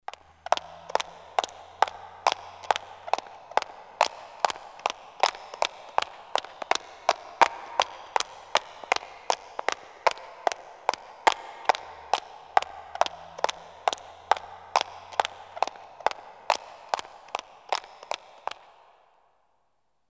急促的高跟鞋在空旷混响强的环境2.mp3
通用动作/01人物/01移动状态/高跟鞋/急促的高跟鞋在空旷混响强的环境2.mp3